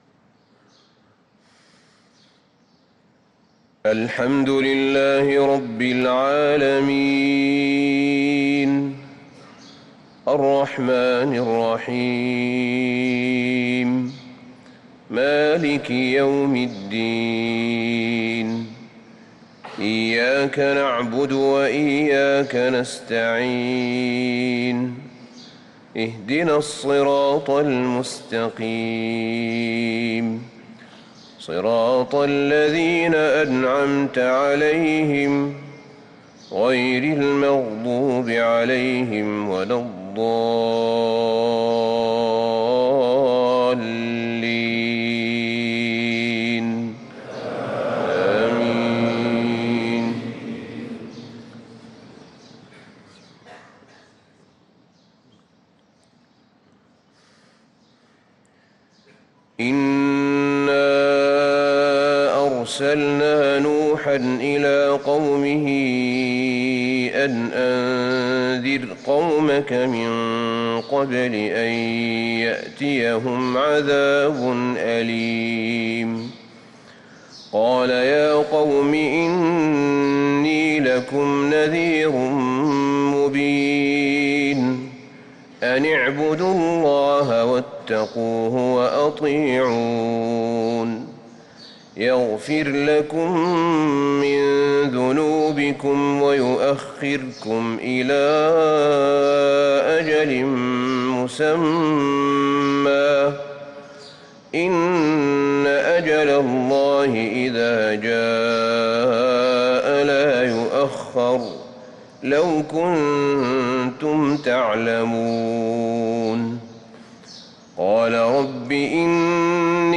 صلاة الفجر للقارئ أحمد بن طالب حميد 22 ربيع الأول 1445 هـ
تِلَاوَات الْحَرَمَيْن .